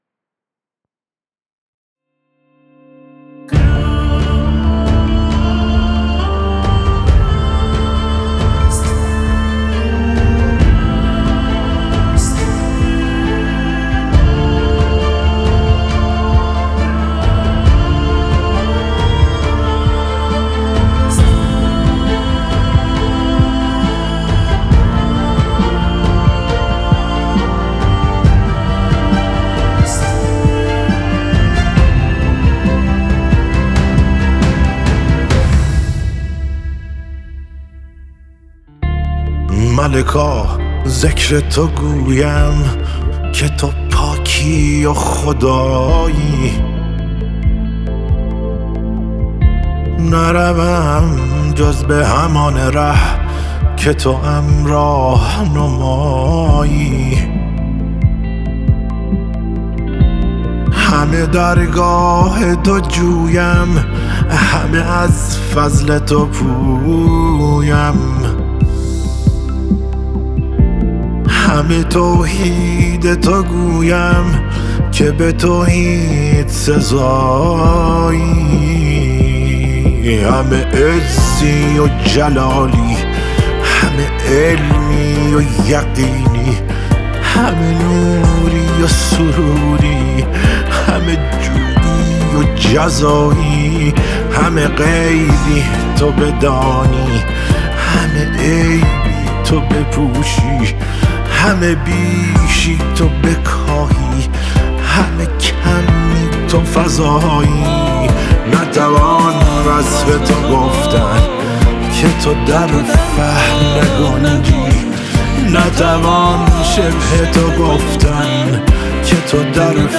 1 آخرین مطالب موسیقی موسیقی پاپ